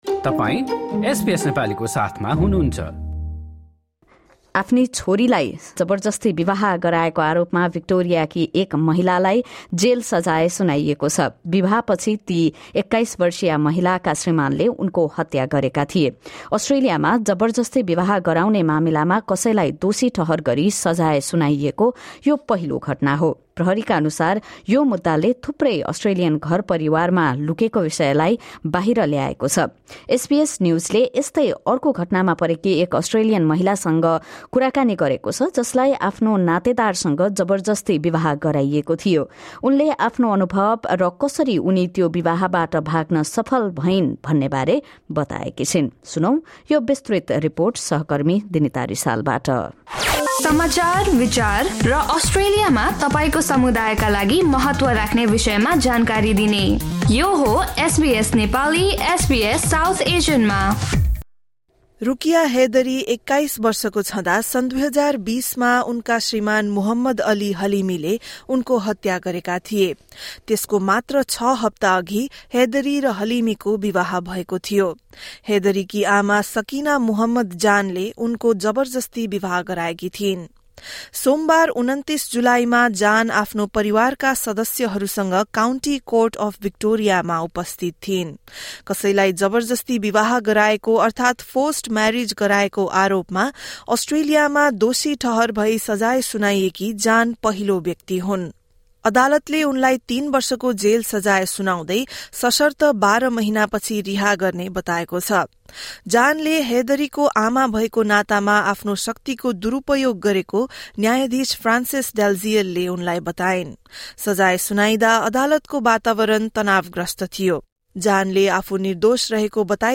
अस्ट्रेलियामा जबरजस्ती विवाह गराउने मामिलामा कसैलाई दोषी ठहर गरी सजाय सुनाइएको यो पहिलो घटना हो। यस विषयमा एसबीएस नेपालीबाट यो विस्तृत रिपोर्ट सुन्नुहोस्।